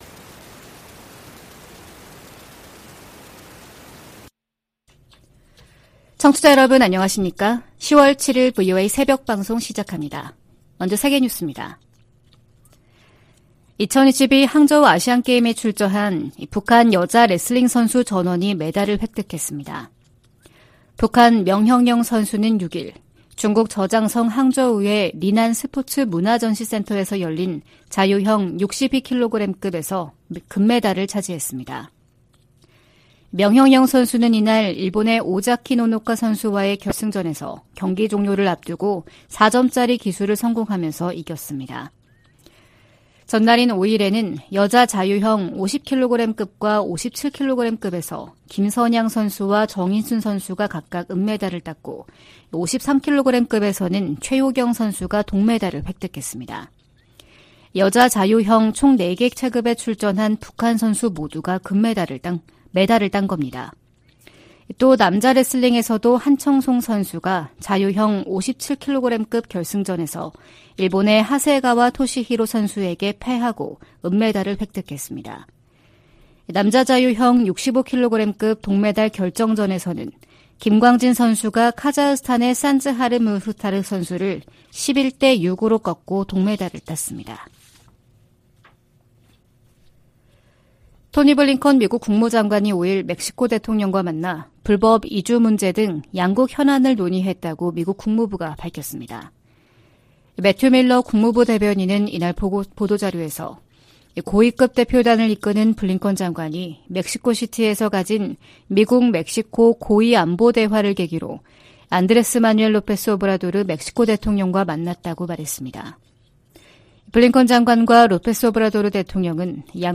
VOA 한국어 '출발 뉴스 쇼', 2023년 10월 7일 방송입니다. 미 국방부는 최근 공개한 대량살상무기(WMD) 대응 전략을 정치·군사적 도발로 규정한 북한의 반발을 일축했습니다.